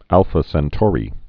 (ălfə sĕn-tôrē)